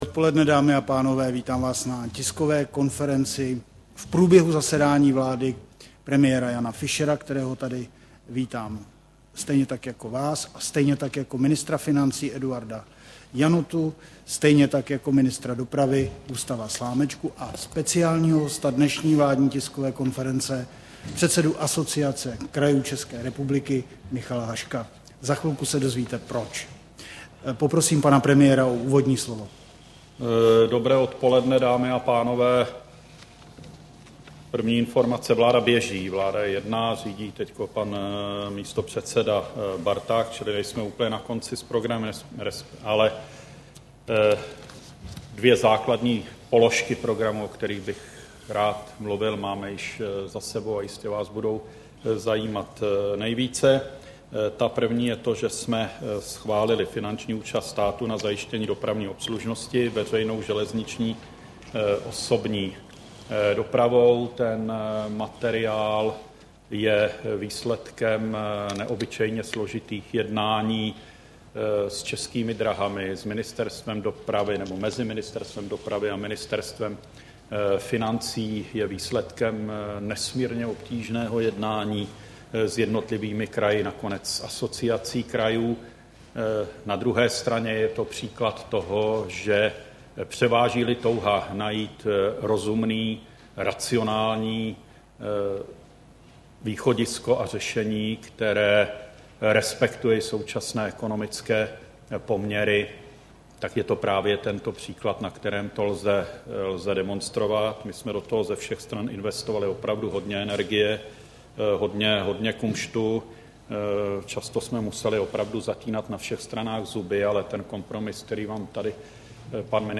Tisková konference po zasedání vlády, 31. srpna 2009